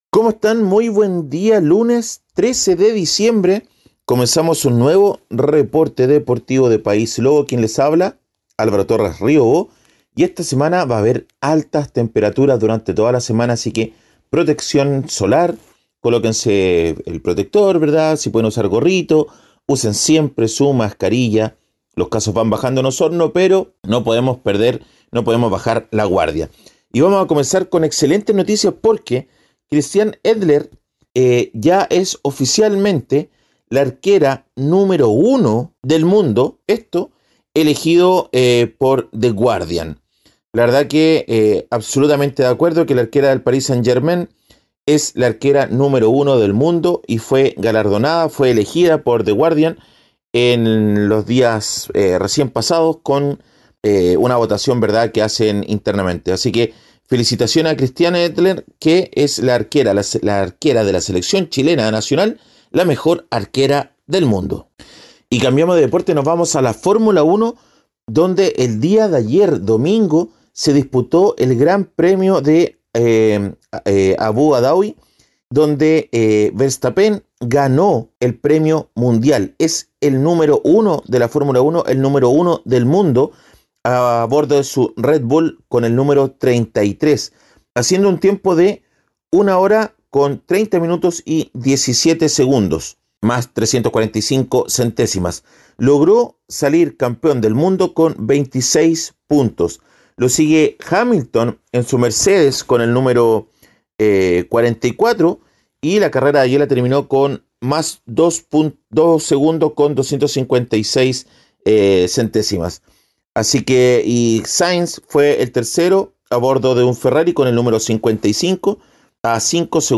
Reporte Deportivo ▶ Podcast 13 de diciembre 2021